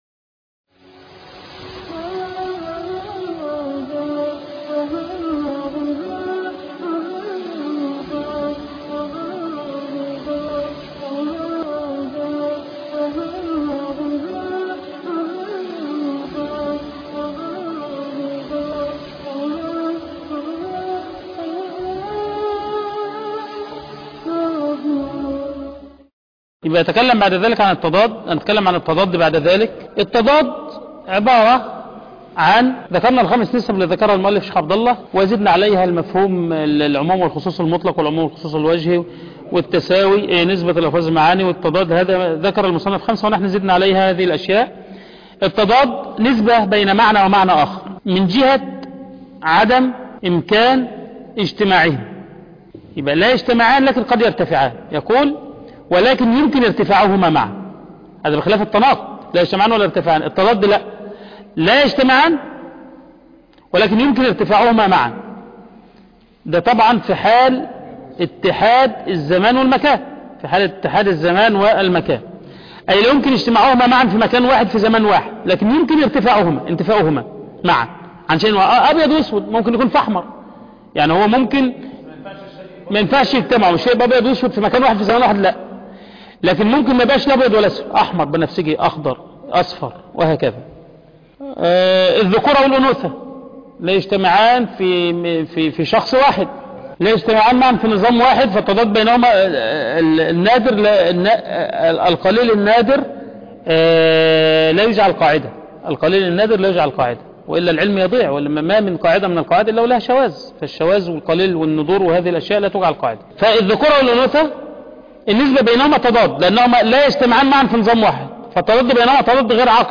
شرح متن السلم المنورق فى علم المنطق - المحاضرة الرابعة